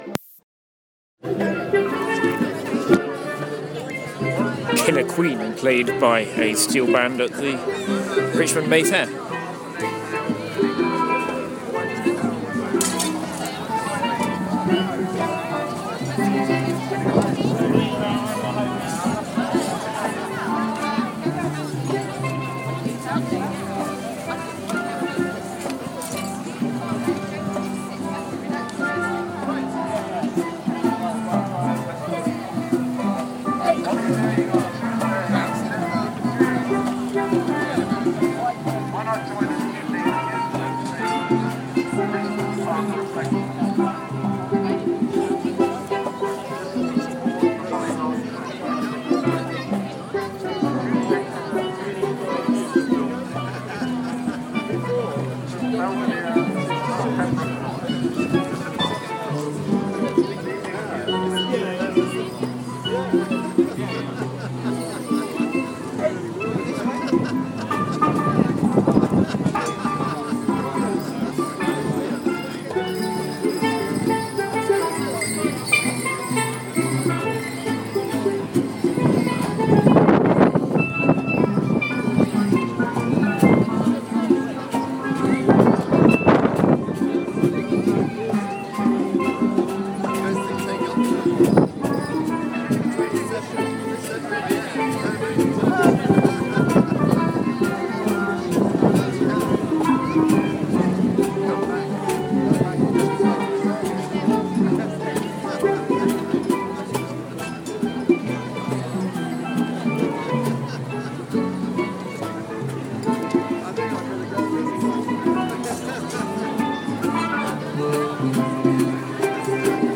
Calypso Killer Queen at Richmond's May Fair 2014